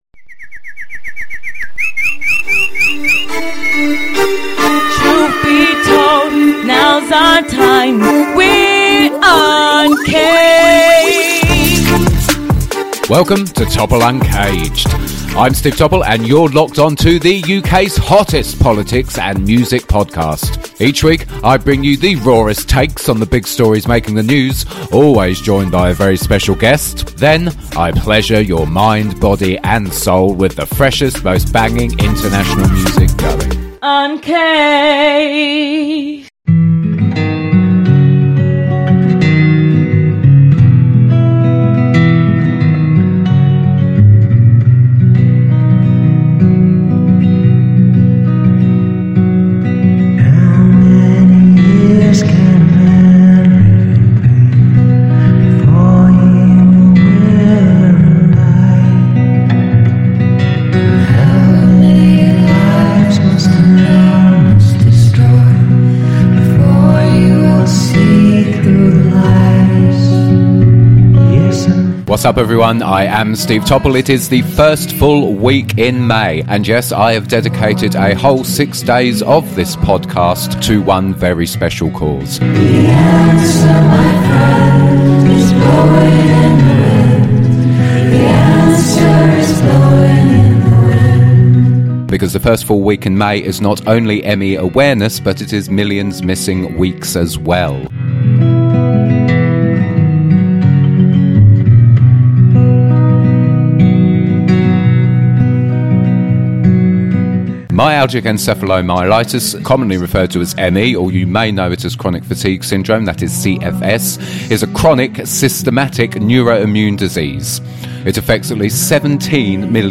THIS PODCAST CONTAINS LANGUAGE AND CONTENT SOME PEOPLE MAY FIND OFFENSIVE.